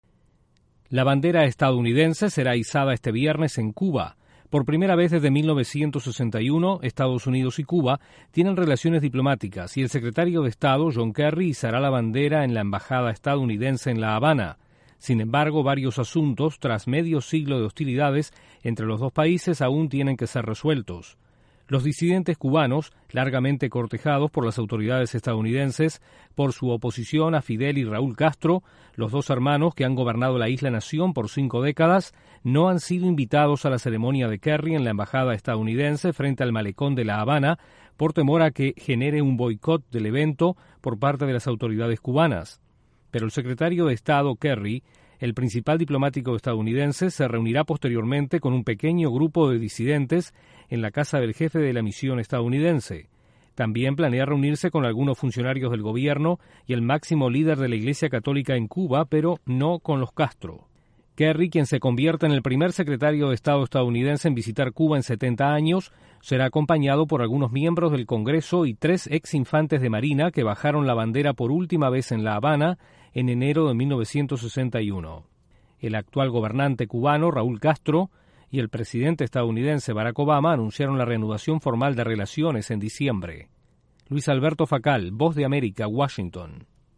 Estados Unidos izará la bandera en Cuba este viernes por primera vez desde 1961. Desde la Voz de América en Washington informa